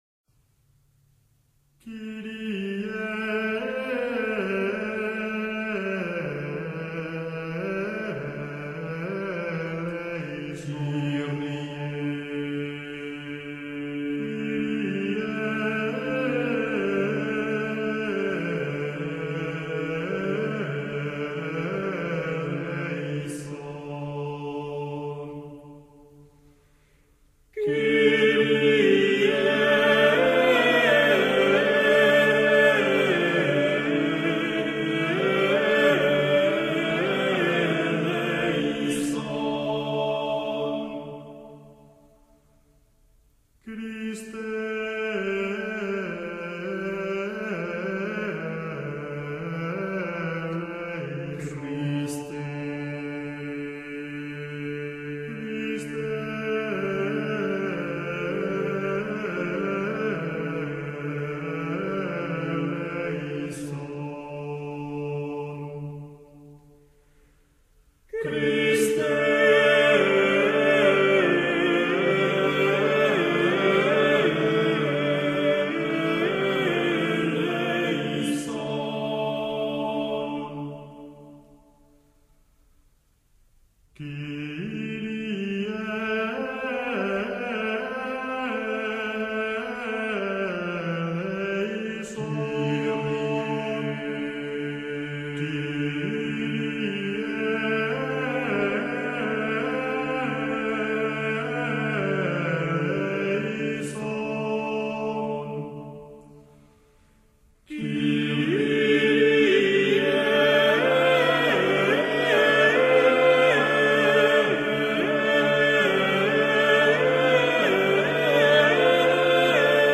d) La polyphonie
la polyphonie est la combinaison de plusieurs mélodies ou de parties musicales chantées ou jouées en même temps.
Audition n° 5 : Kyrie par l'Ensemble Organum